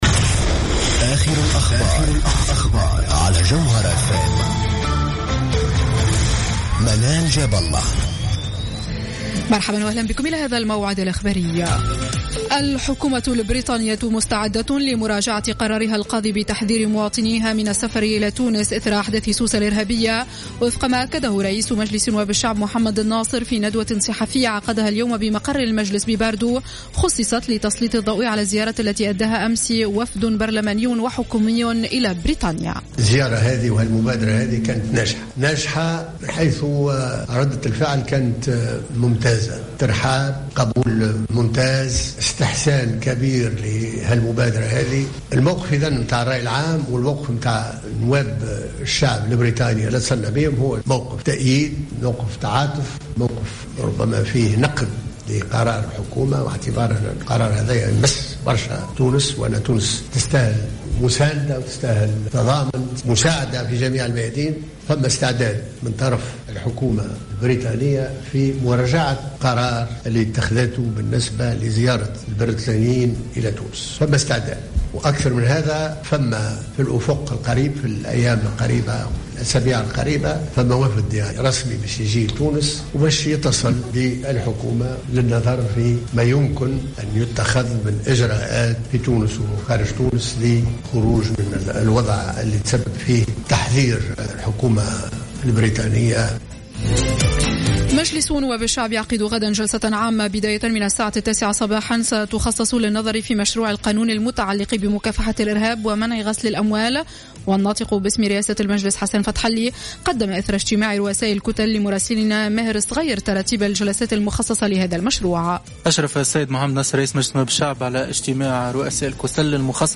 نشرة أخبار السابعة مساء ليوم الثلاثاء 21 جويلية 2015